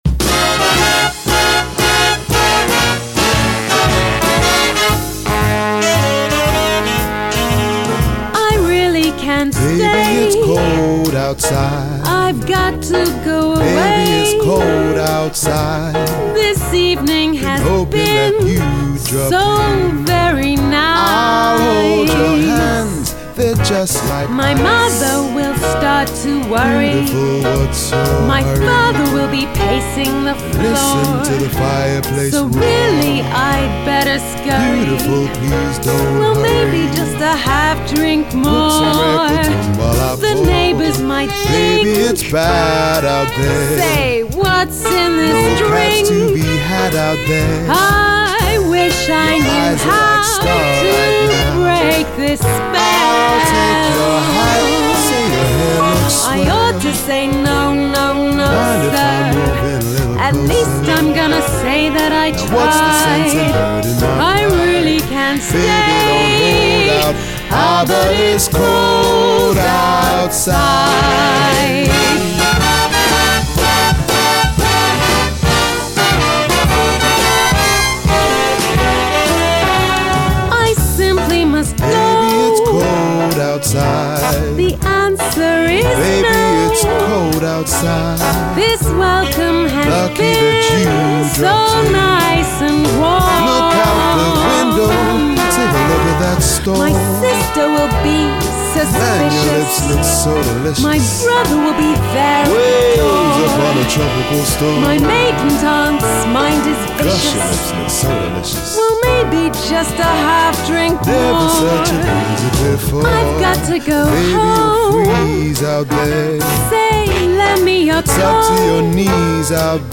Jazz Vocals